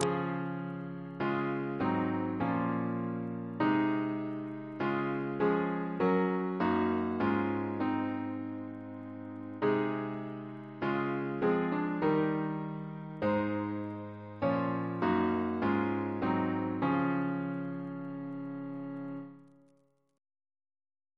Double chant in D♭ Composer: Joseph M. Fox (d.1911) Reference psalters: ACB: 95